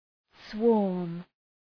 {swɔ:rm}